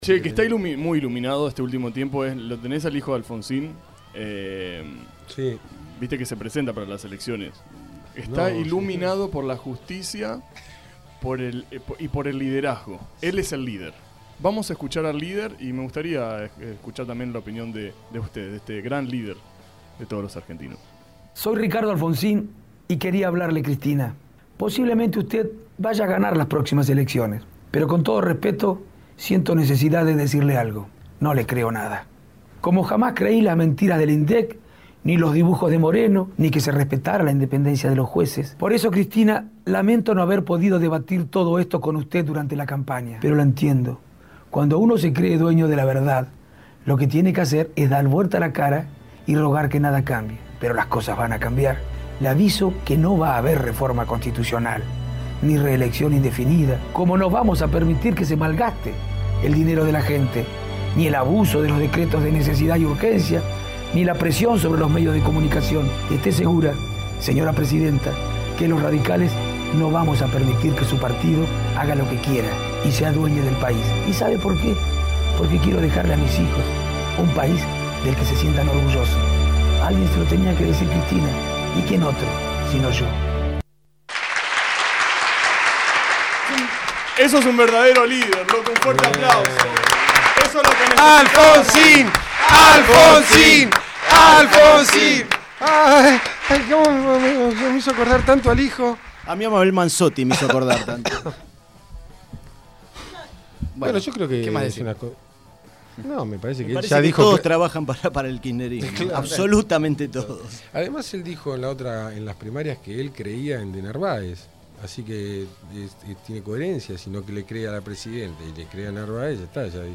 En seis fragmentos la entrevista completa para disfrutarla.